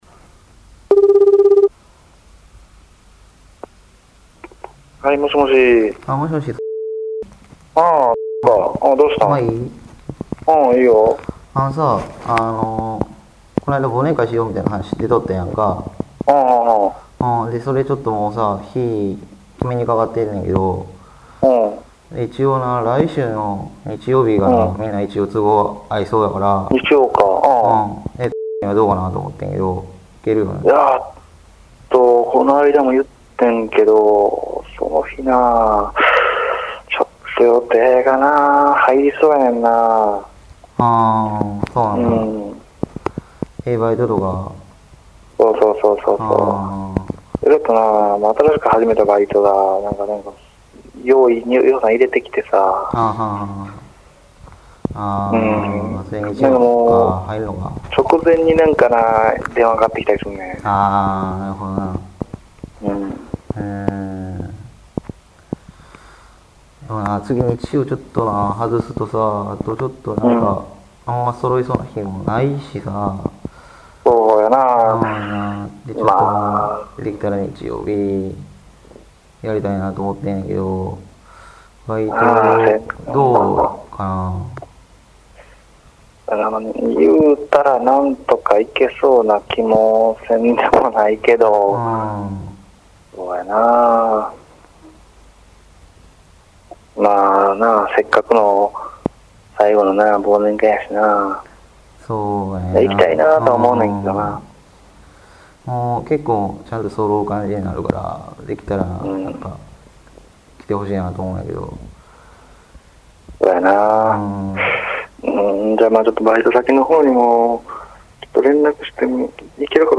方言ロールプレイ会話データベース ペア入れ替え式ロールプレイ会話＜関西＞
関西若年層男性ペア１